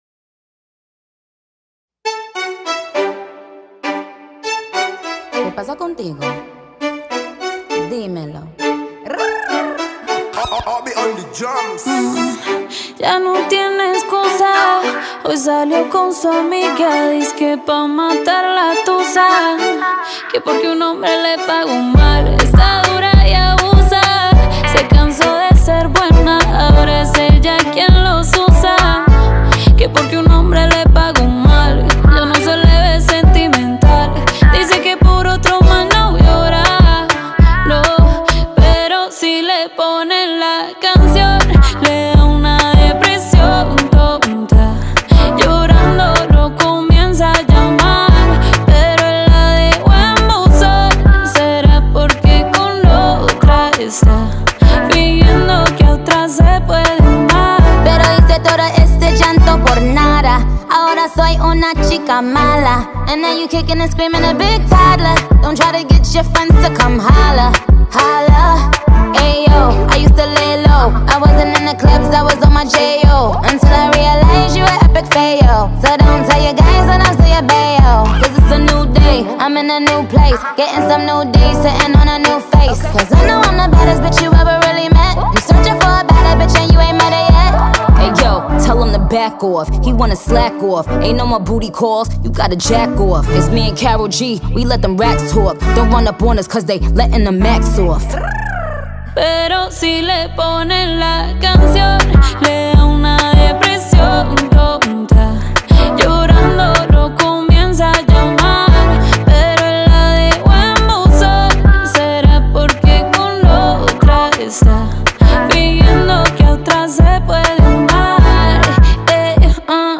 آهنگ‌های شاد و اینستاگرامی